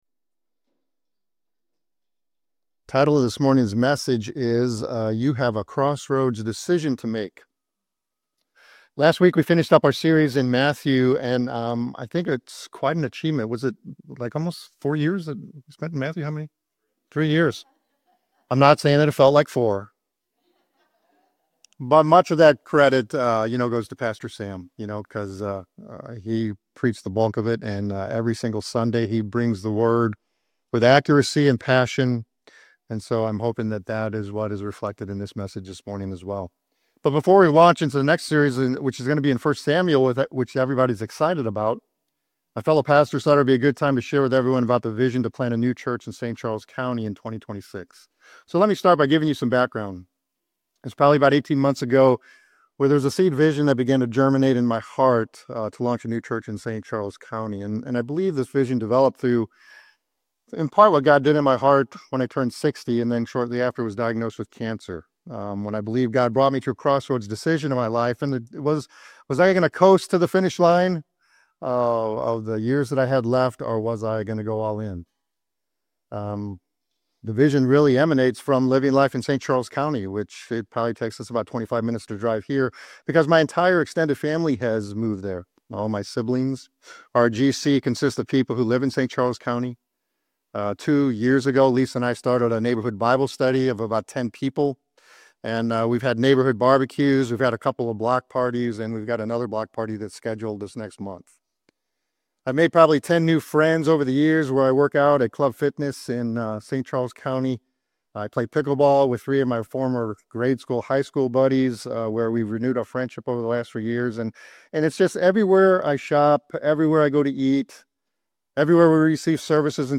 Drawing from personal experiences and biblical insights, the sermon challenges listeners to embrace their role in spreading the Gospel. Through the parable of the Sower and the example of Jesus and the Apostle Paul, you'll be encouraged to scatter seeds of faith, engage in spiritual warfare, and fearlessly proclaim the Gospel.